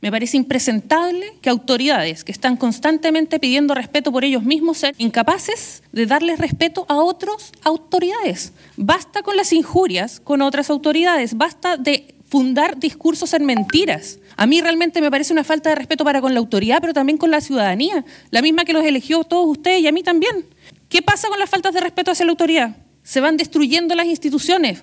Y la aludida representante del Frente Amplio, consejera Patricia Rada, le pareció “impresentable” la situación y pidió terminar con las injurias.